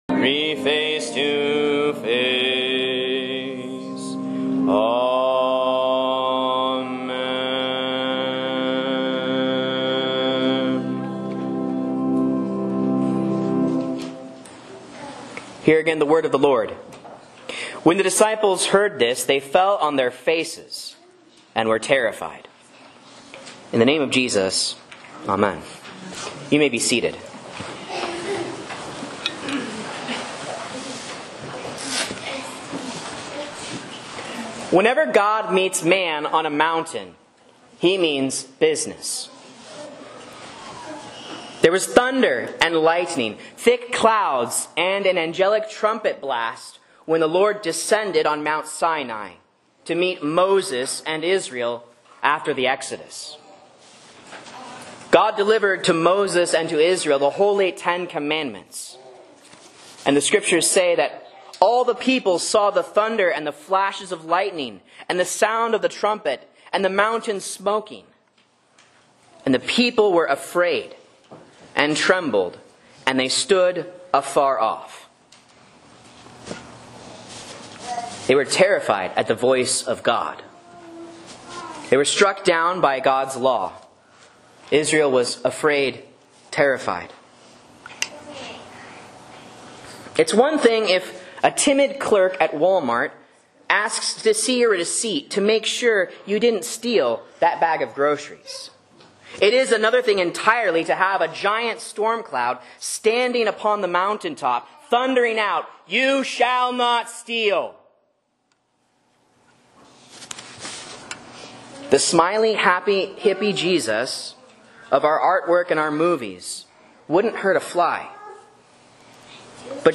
Sermon and Bible Class Audio from Faith Lutheran Church, Rogue River, OR
A Sermon on Matthew 17:1-9 for Transfiguration (A)